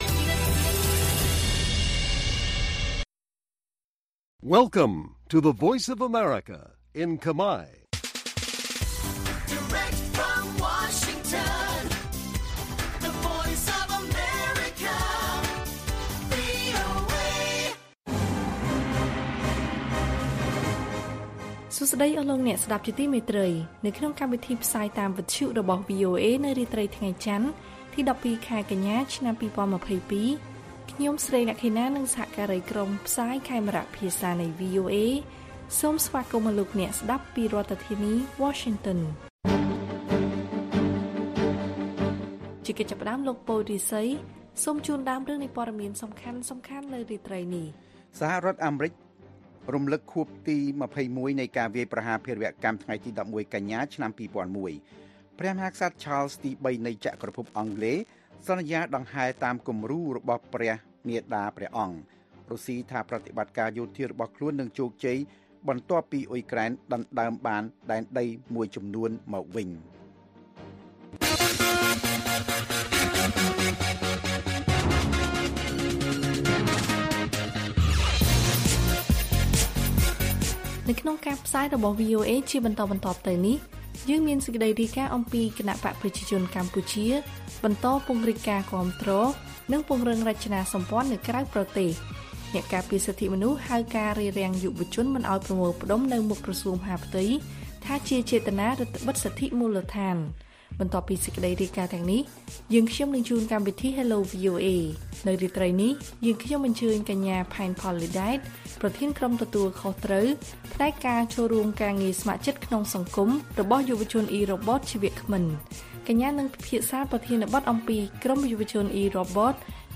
ព័ត៌មានពេលរាត្រី ១២ កញ្ញា៖ ព្រះមហាក្សត្រ Charles ទី ៣ នៃចក្រភពអង់គ្លេសសន្យាដង្ហែតាមគំរូរបស់ព្រះមាតាព្រះអង្គ